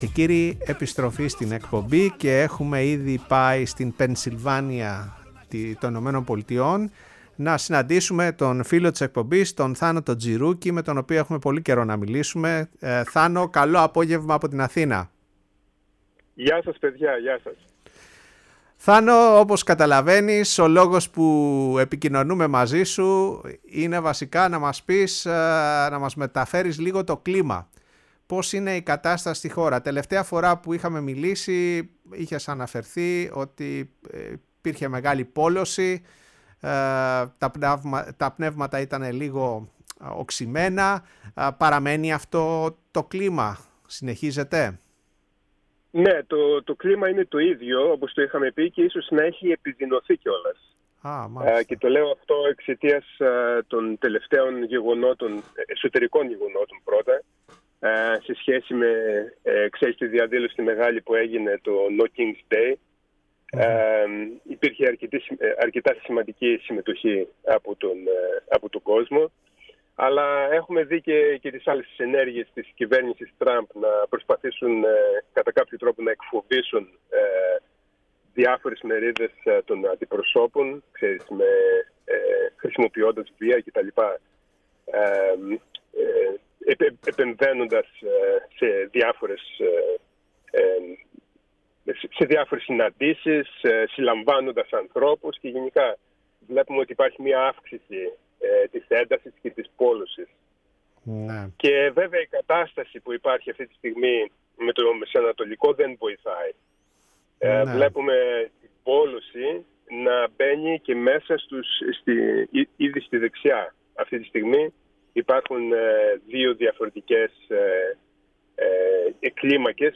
μιλώντας στο ραδιόφωνο της Φωνής της Ελλάδας και στην εκπομπή “Η Παγκόσμια Φωνή μας”